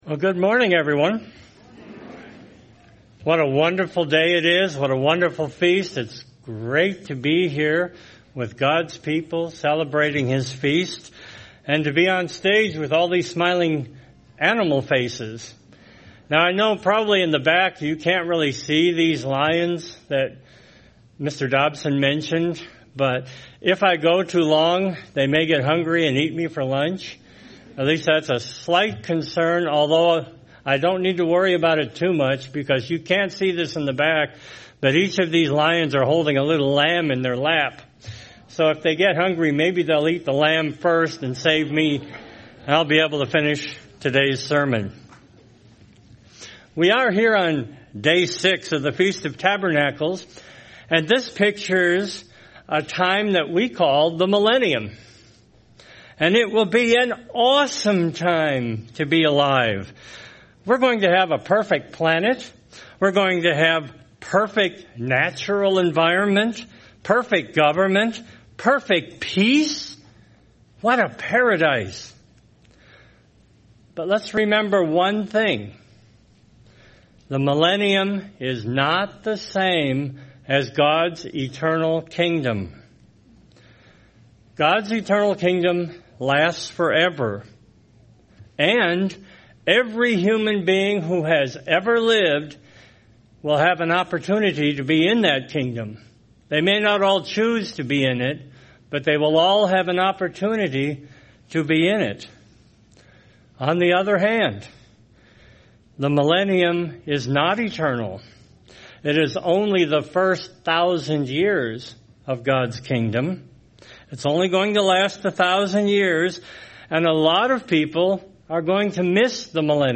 This sermon was given at the Branson, Missouri 2023 Feast site.